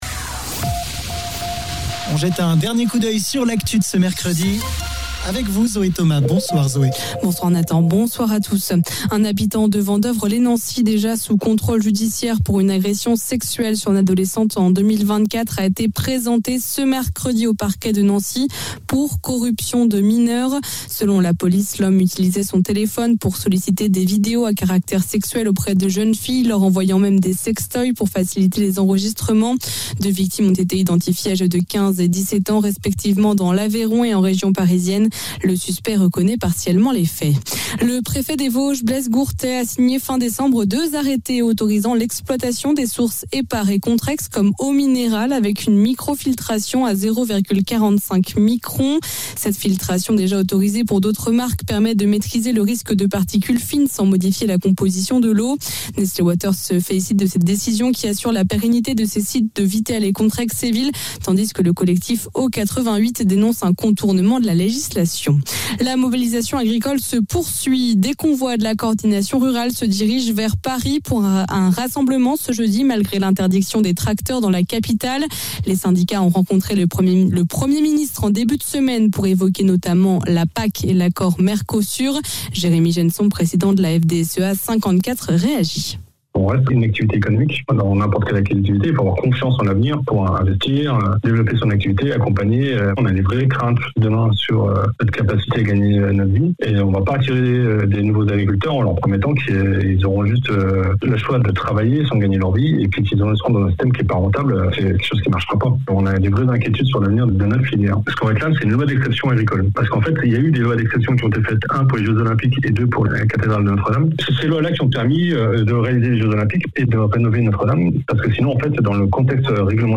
flash informations